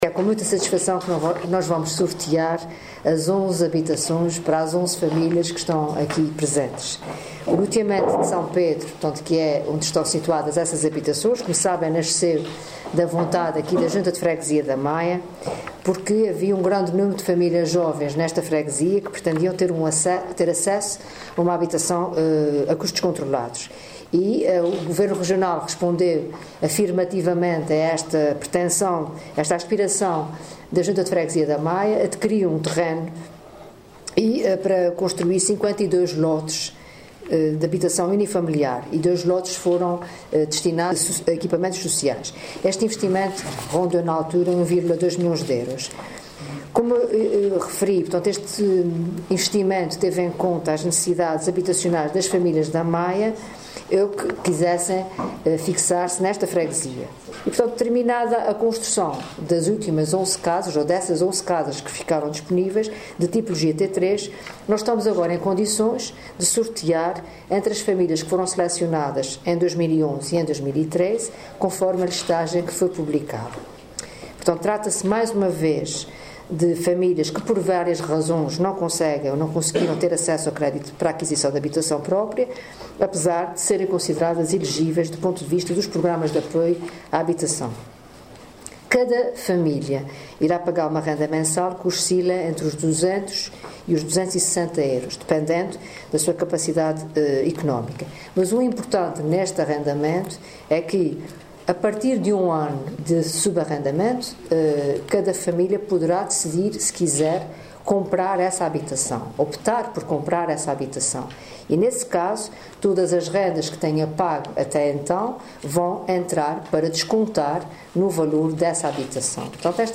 Piedade Lalanda, que falava no sorteio de 11 habitações de tipologia T3, no Loteamento de S. Pedro, na freguesia da Maia, concelho da Ribeira Grande, assegurou que “o direito a uma habitação condigna é uma prioridade para o Governo”.